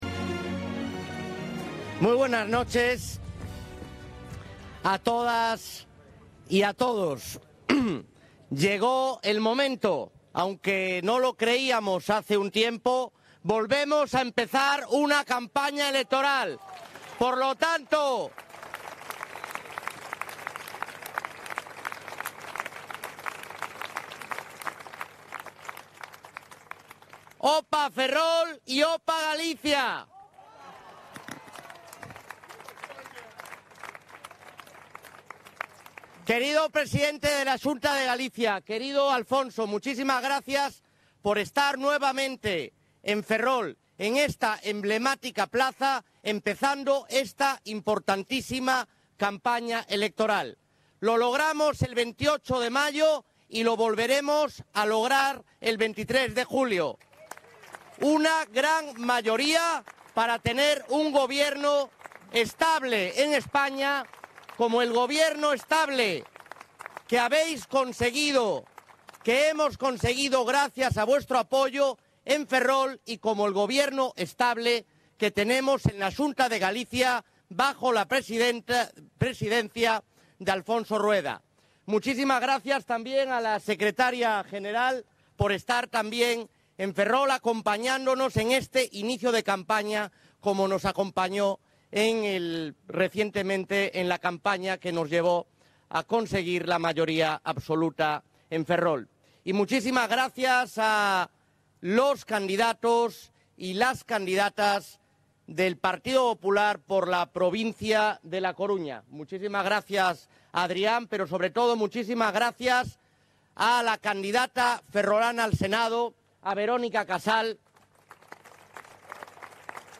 Acto del PP de inicio de campaña de las elecciones generales en Ferrol - Galicia Ártabra Digital
El alcalde de Ferrol, ha ejercido de anfitrión de este acto, y ha solicitado para Alberto Núñez